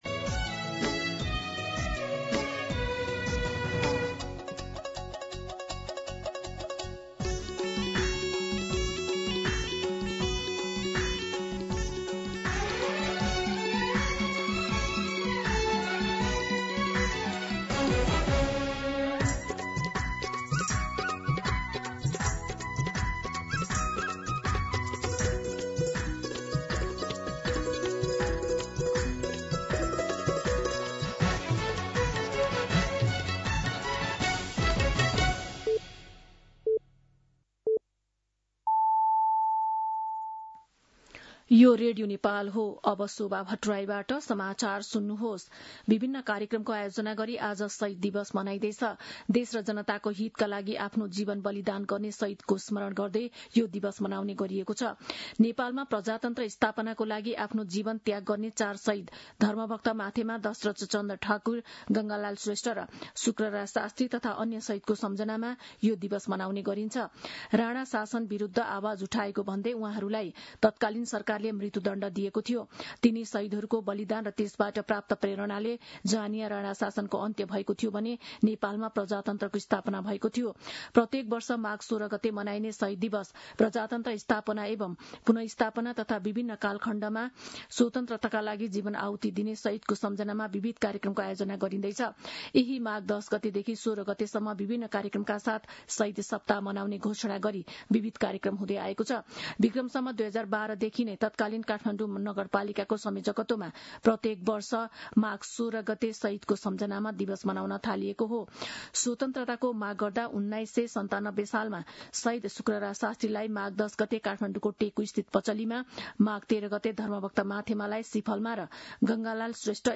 दिउँसो १ बजेको नेपाली समाचार : १६ माघ , २०८२